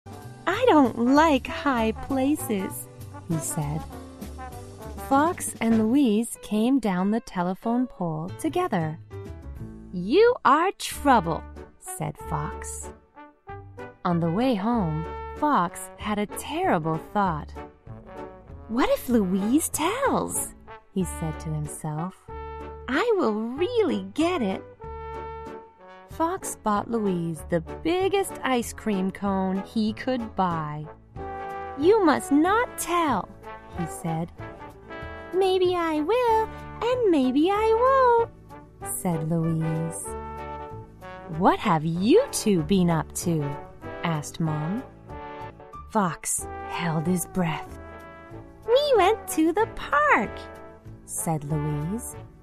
在线英语听力室小狐外传 第5期:恐高的听力文件下载,《小狐外传》是双语有声读物下面的子栏目，非常适合英语学习爱好者进行细心品读。故事内容讲述了一个小男生在学校、家庭里的各种角色转换以及生活中的趣事。